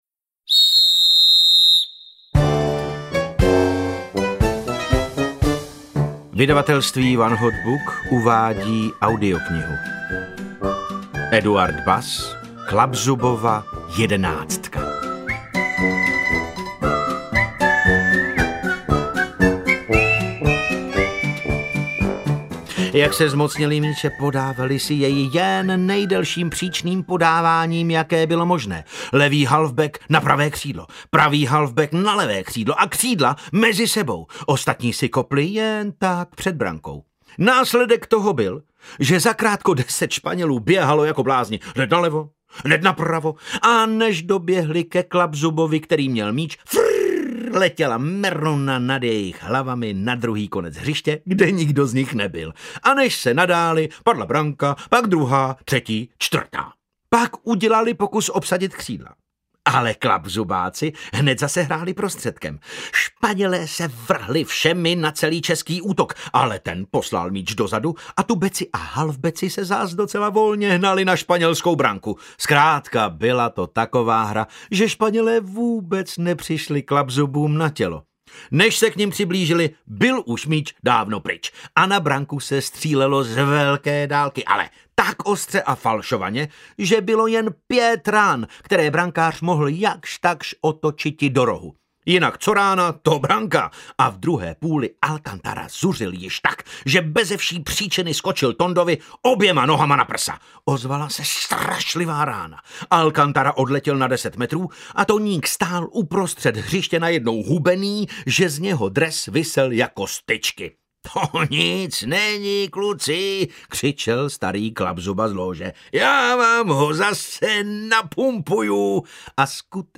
Klapzubova jedenáctka audiokniha
Ukázka z knihy
• InterpretDavid Novotný